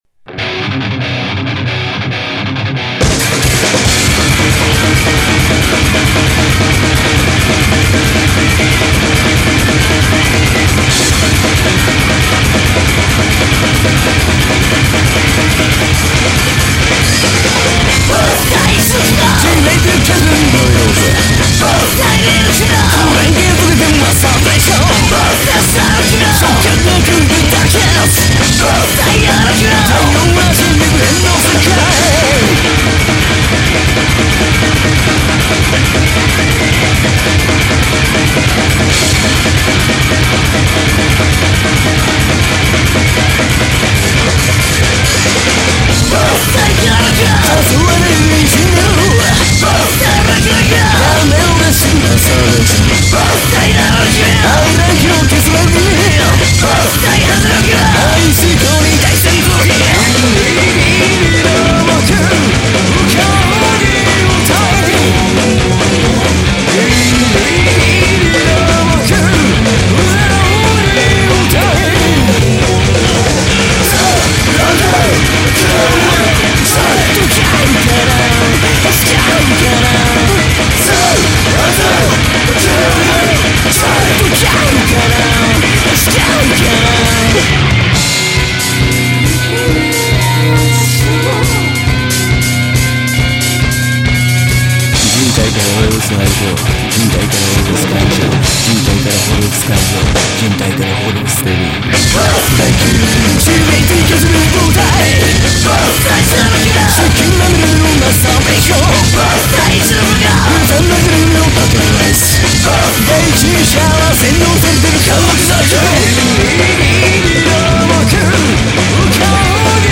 not exactly a bubble-gum, happy pop track